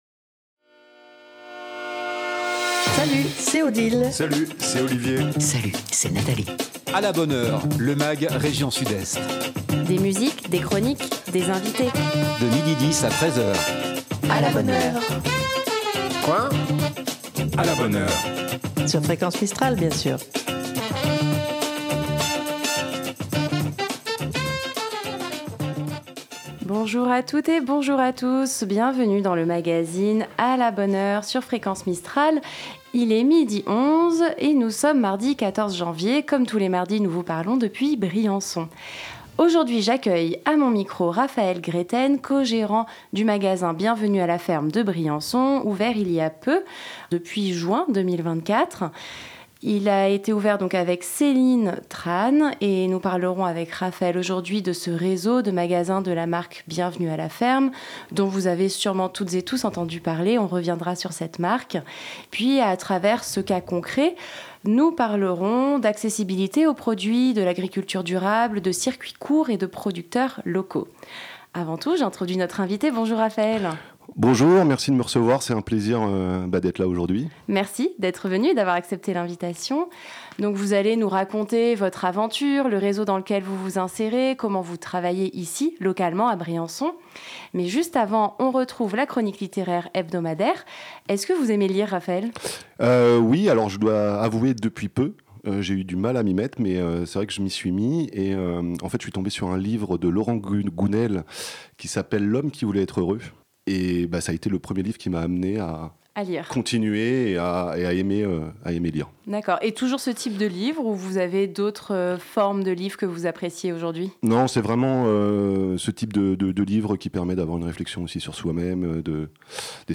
des invité.e.s en direct